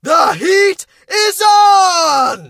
fang_ulti_vo_01.ogg